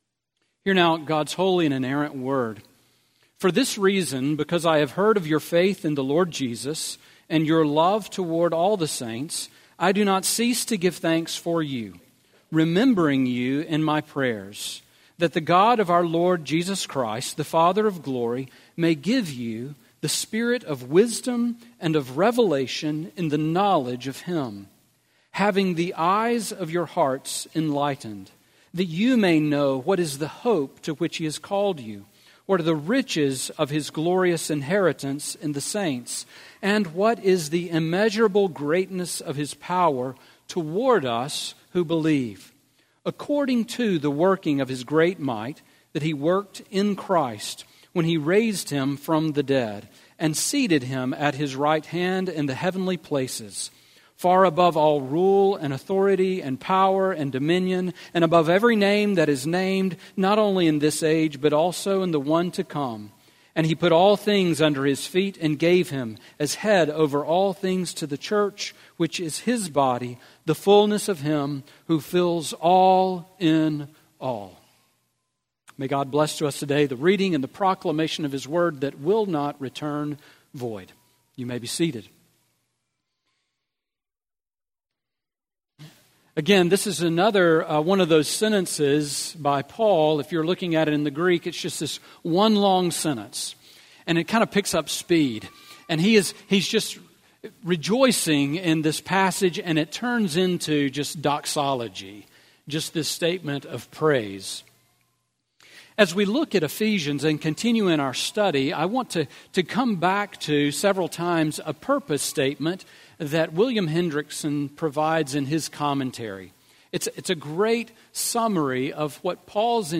Sermon on Ephesians 1:15-23 from September 23